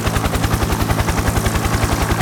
propellers.ogg